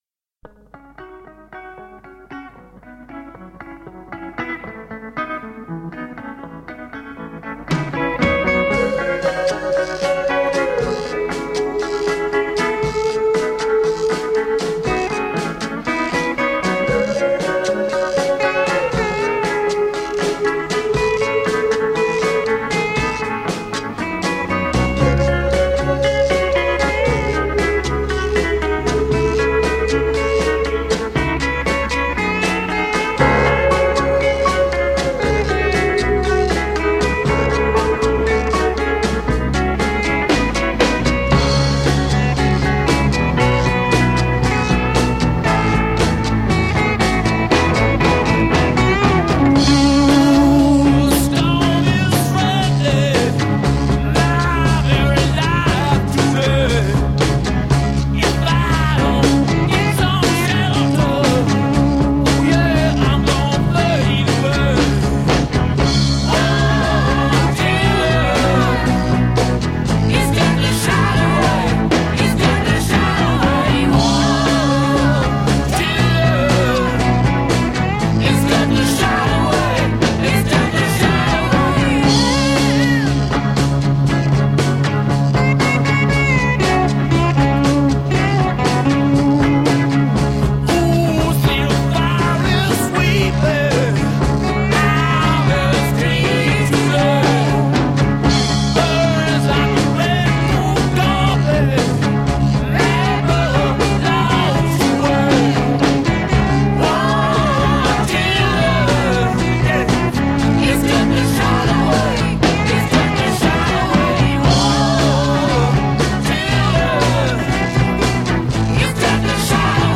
I’m talking about isolated vocal tracks from classic songs.
Forget ghostly — this one is just plain scary.